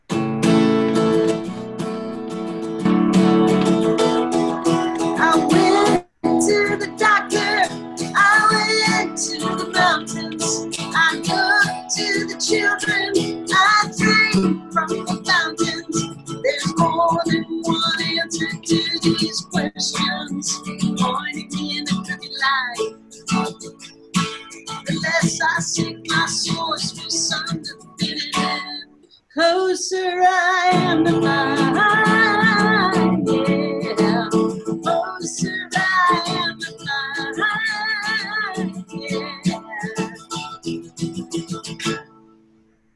(captured from the youtube video stream)